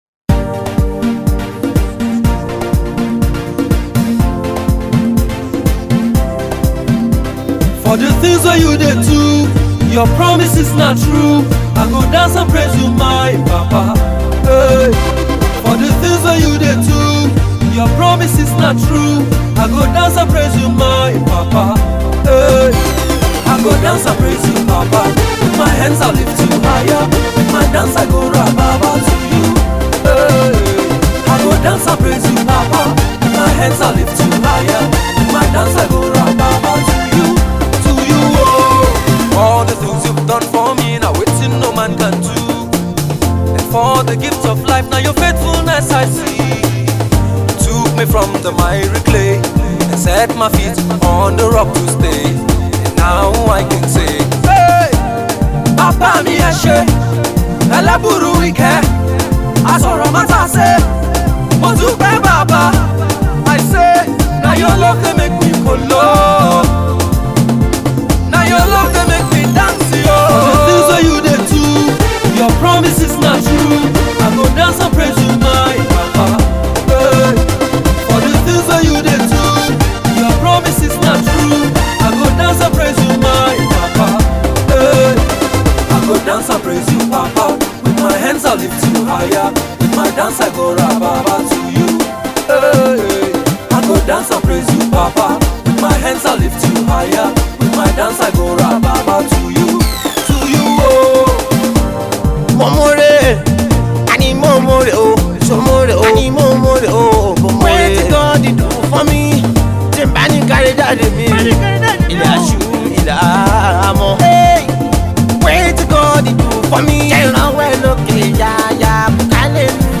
groovy song of thanksgiving
‘easy-to-sing-along’ tune
gospel singer